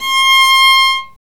Index of /90_sSampleCDs/Roland - String Master Series/STR_Viola Solo/STR_Vla3 Arco nv
STR VIOLA 0M.wav